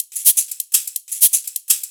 Live Percussion A 03.wav